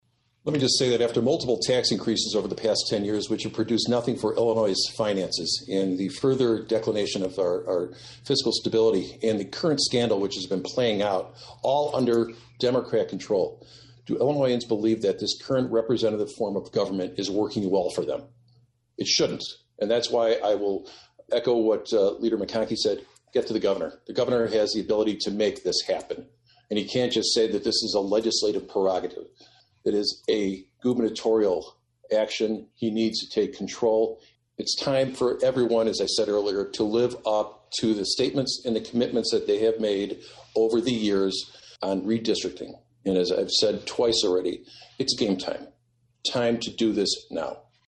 Attached is audio with Leader Durkin, saying Illinois residents should contact the Governor’s office and let their feelings on the Illinois legislative redistricting maps be known to the governor.